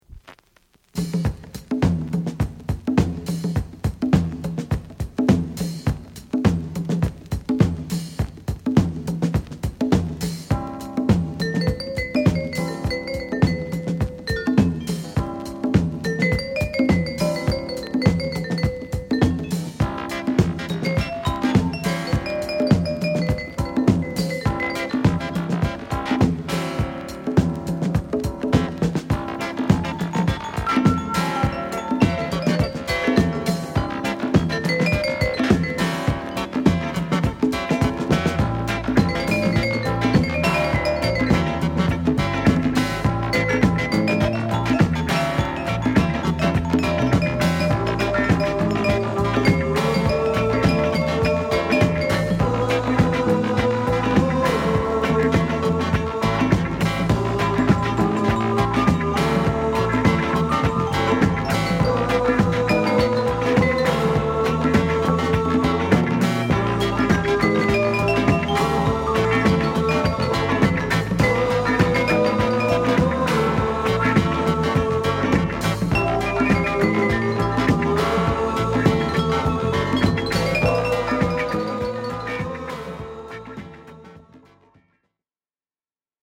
ドラムブレイク 試聴
ファットなドラムブレイクから流麗なヴァイブを交えジャジーなサウンドに仕上げた展開も抜群。